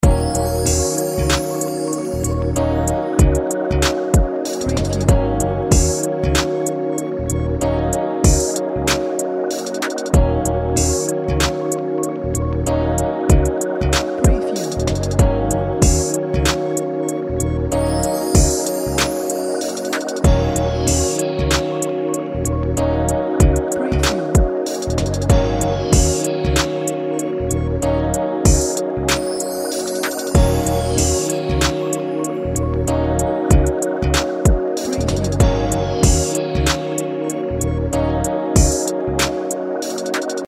Relaxing background story telling hip hop music loop